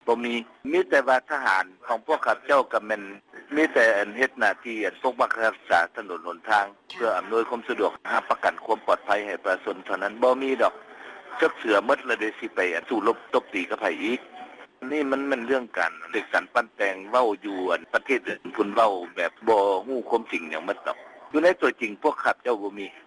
ເຖິງຢ່າງໃດ ກໍຕາມ, ທ່ານ ຢົງ ຈັນທະລັງສີ, ໂຄສົກກະຊວງການຕ່າງປະເທດລາວ, ປະຕິເສດ ຕໍ່ຣາຍງານ ດັ່ງກ່າວ ວ່າ: